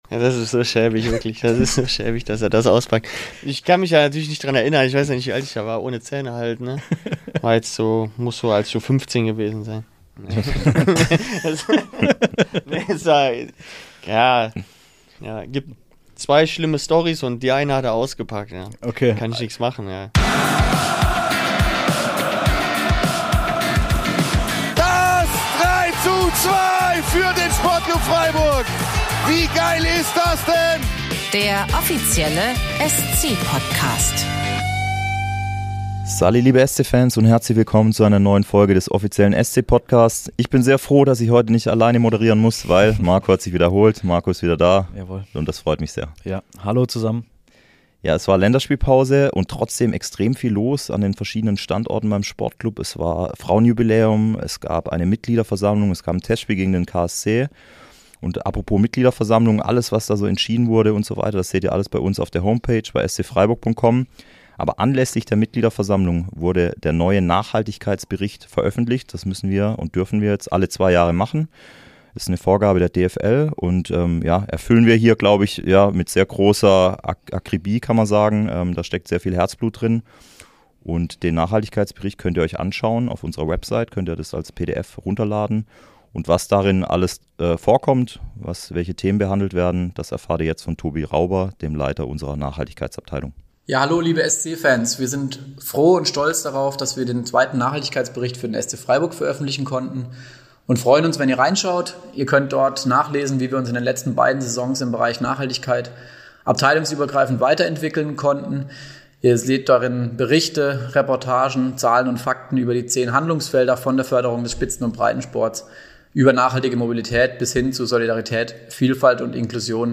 Das Interview mit ihm gibt es auch als Video auf dem SC-Youtube-Kanal.